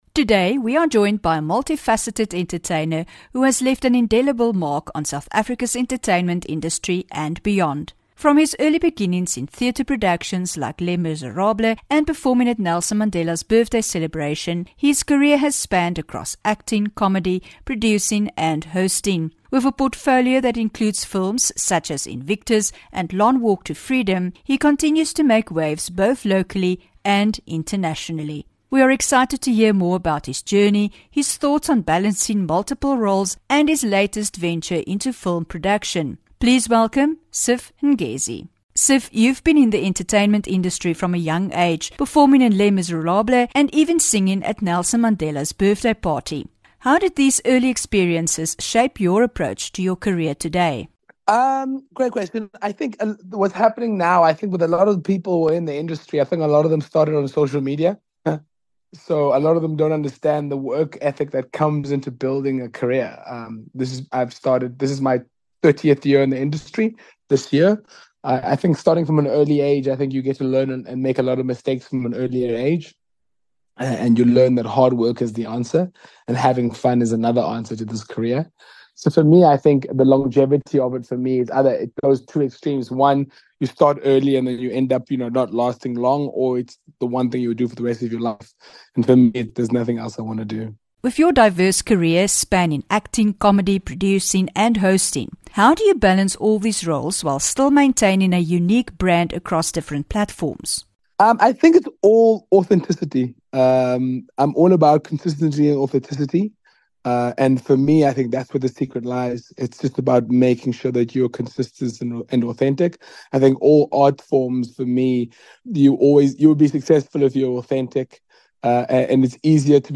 8 Oct INTERVIEW: MULTIFACETED ENTERTAINER, SIV NGESI